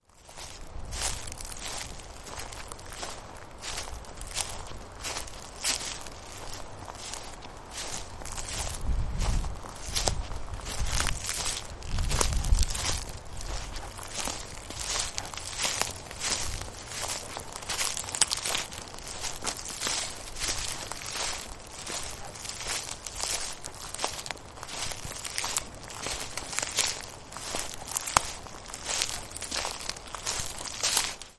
Spring trip to Yosemite Valley
My footsteps
017_my_footsteps_pineDuff.mp3